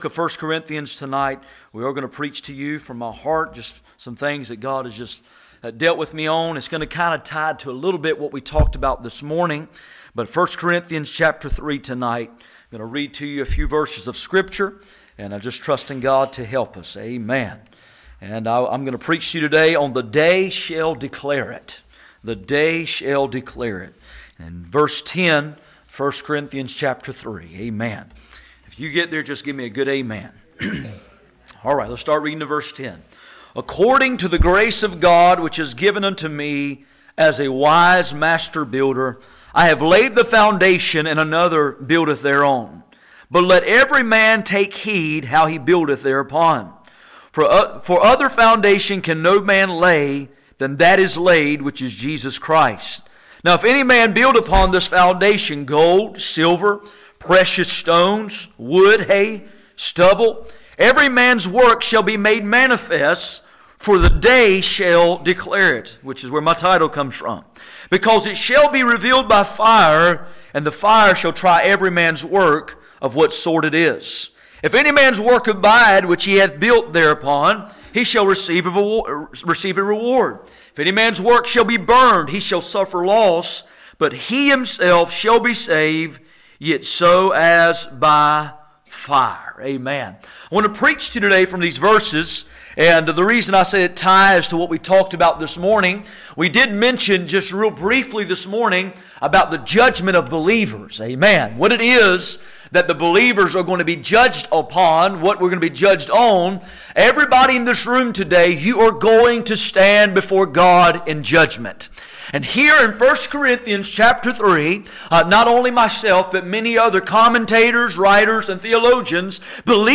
None Passage: 1 Corinthians 3:10-13 Service Type: Sunday Evening %todo_render% « He’s coming